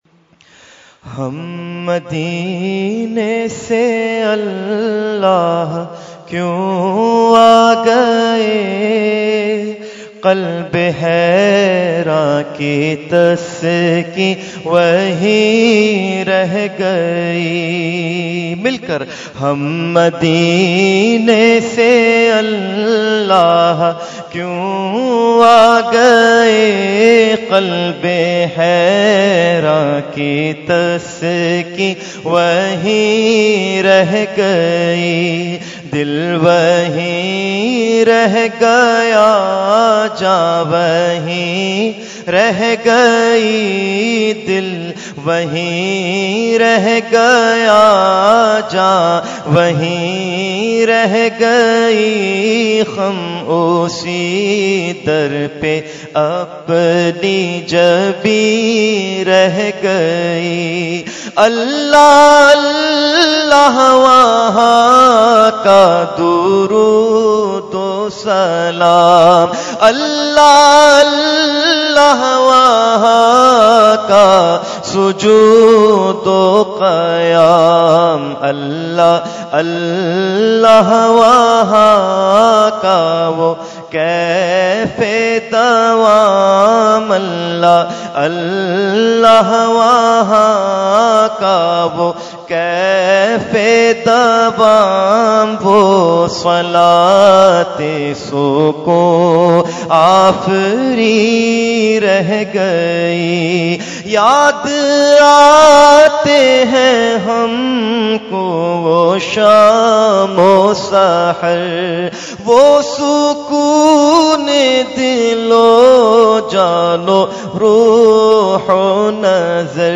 Category : Naat | Language : UrduEvent : Khatam Hizbul Bahr 2016